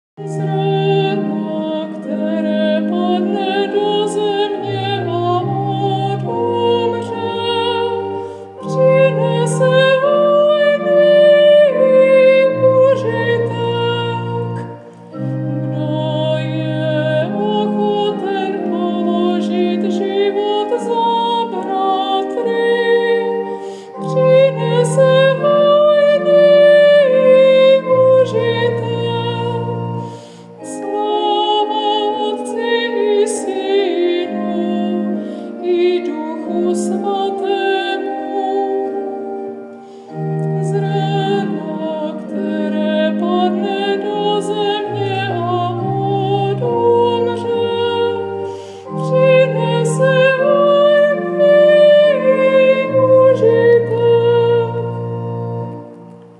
Nápěv zpěvu pro krátkém čtení
Zpev-po-kratkem-cteni_cut_56sec.mp3